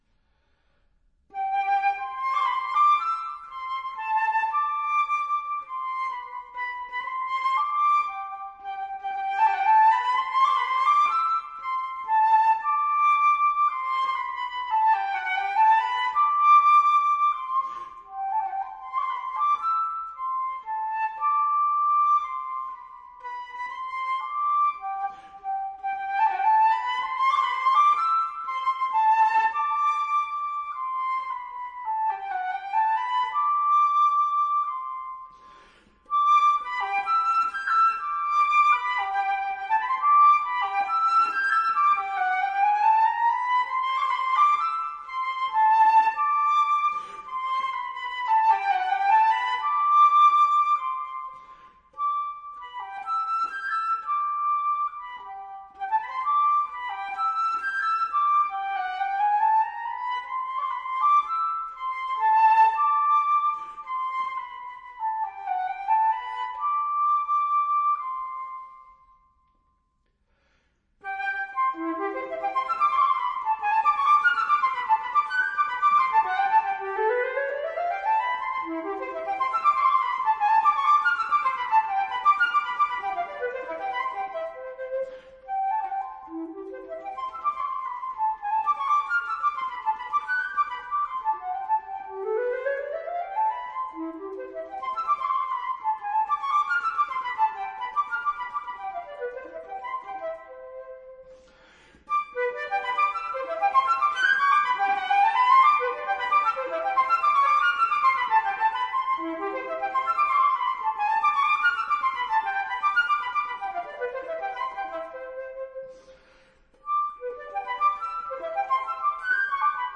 試聽二可以聽到旋律再重複時，以較小、柔軟的聲響來表現。